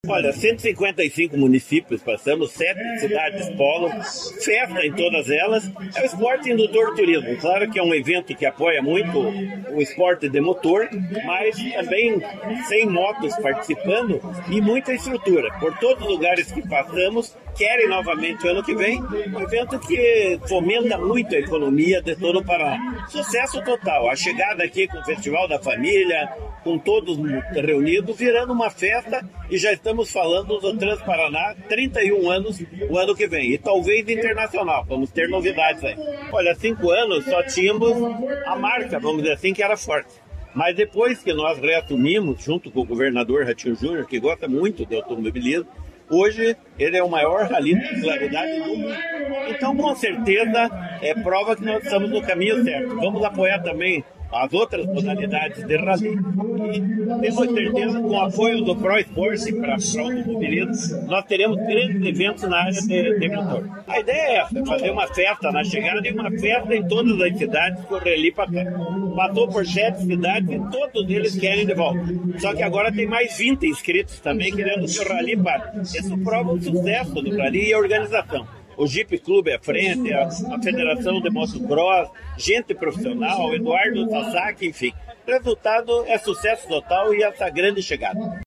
Sonora do secretário do Esporte, Helio Wirbiski, sobre a final da 30ª edição do Rally de Regularidade Transparaná